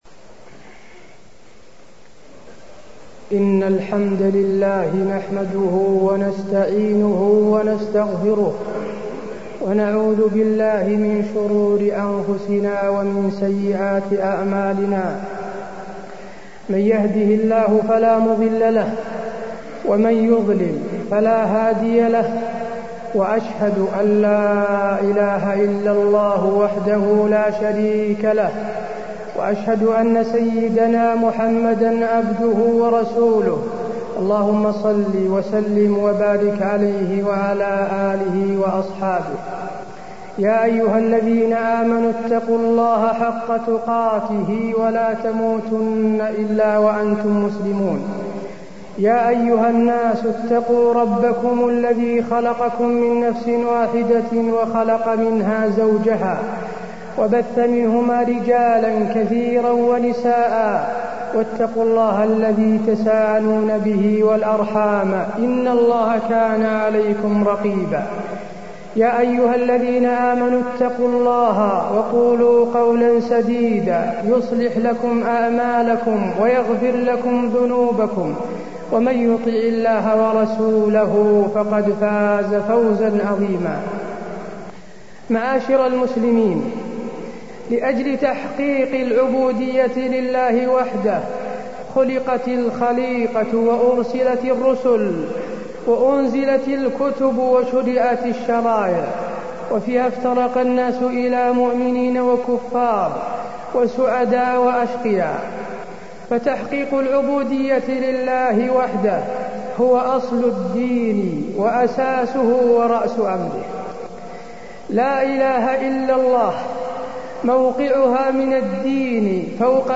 تاريخ النشر ١٩ شعبان ١٤٢٣ هـ المكان: المسجد النبوي الشيخ: فضيلة الشيخ د. حسين بن عبدالعزيز آل الشيخ فضيلة الشيخ د. حسين بن عبدالعزيز آل الشيخ معنى لا إله إلا الله